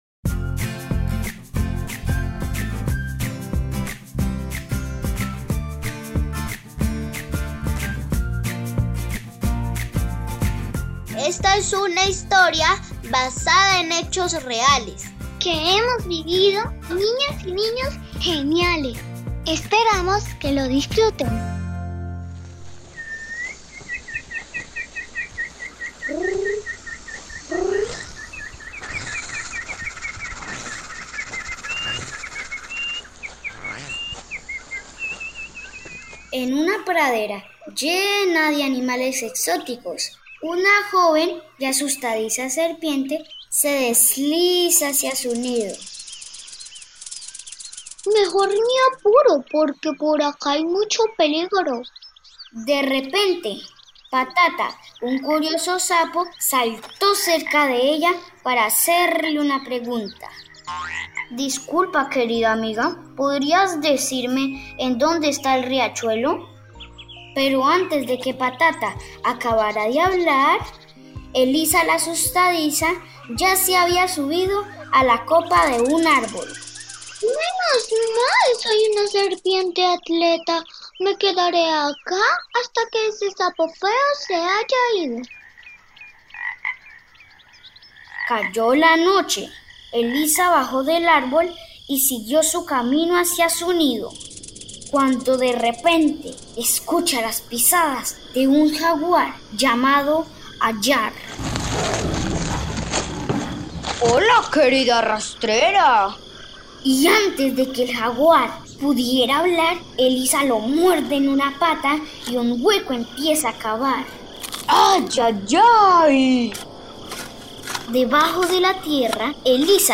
..Niños y niñas colombianos interpretan el radioteatro de una serpiente asustadiza, vive una gran aventura junto a una nueva amiga.